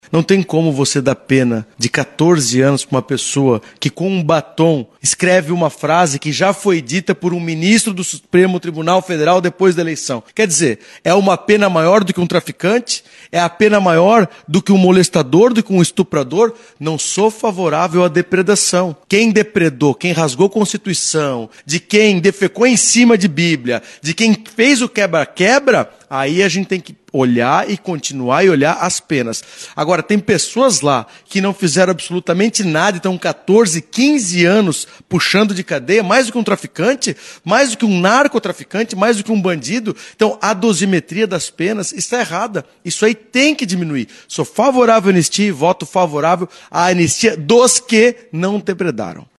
O deputado federal Fabio Schiochet, do União Brasil, se manifestou a favor da anistia.